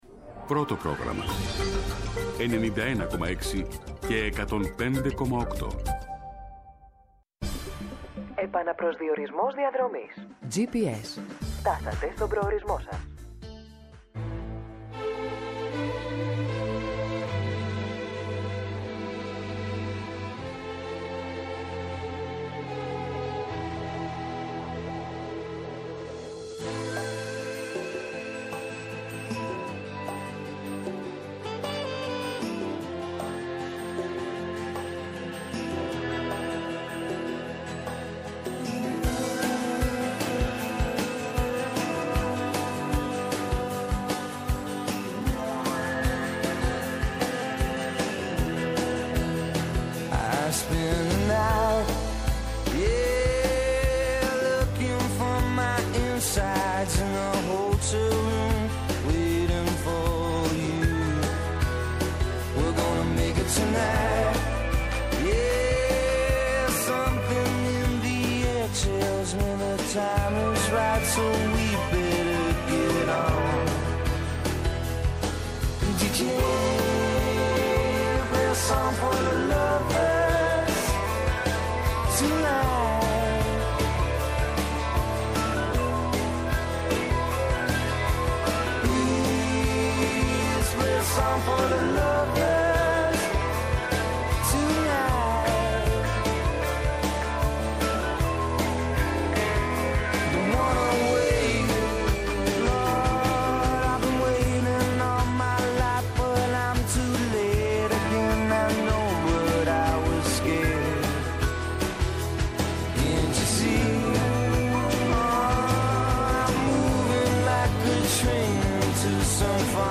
-Ο Θανάσης Κοντογεώργης, υφυπουργός παρά τω Πρωθυπουργώ.